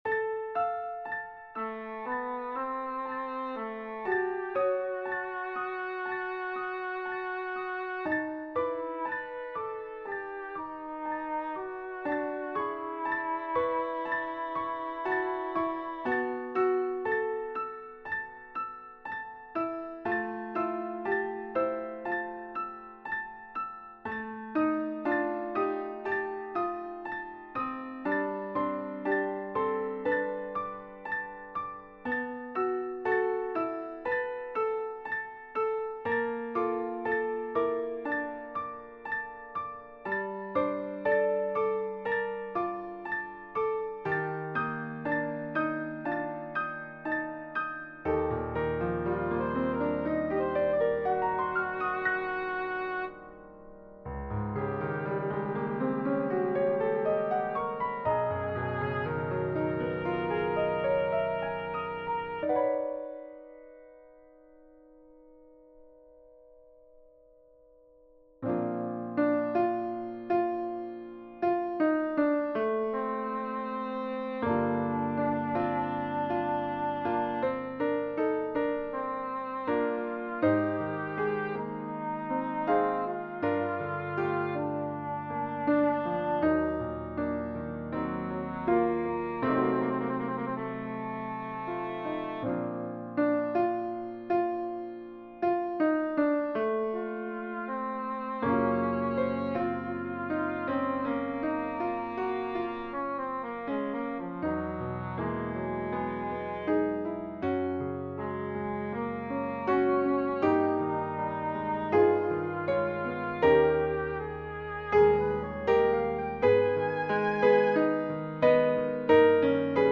SSA female choir and piano